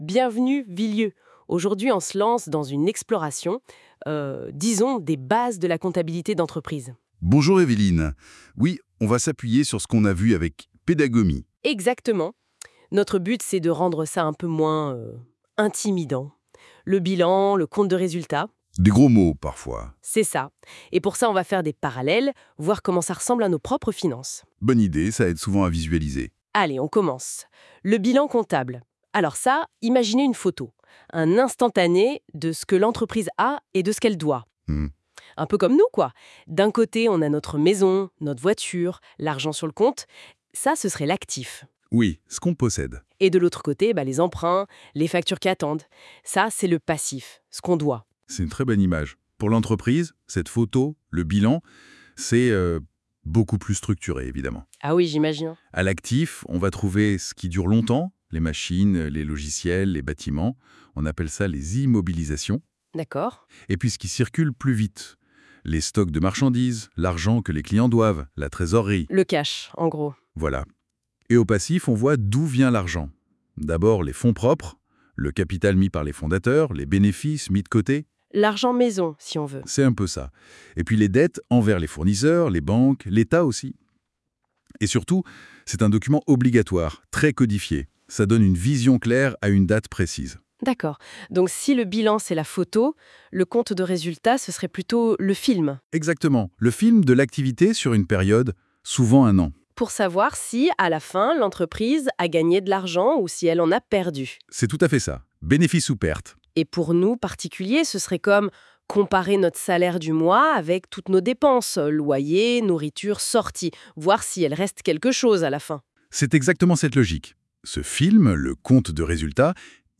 Cours audio